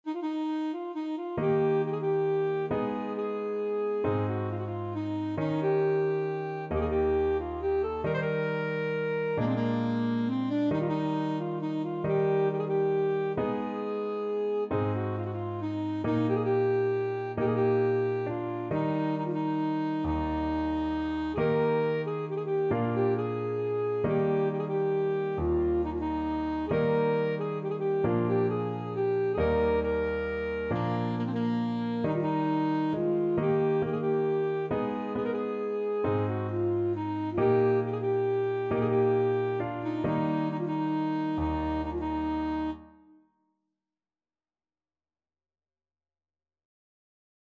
World Trad. Joc In Patru (Romanian Folk Song) Alto Saxophone version
Alto Saxophone
Traditional Music of unknown author.
One in a bar .=45
Eb major (Sounding Pitch) C major (Alto Saxophone in Eb) (View more Eb major Music for Saxophone )
3/4 (View more 3/4 Music)
Bb4-Bb5
World (View more World Saxophone Music)
romania_joc_in_patru_ASAX.mp3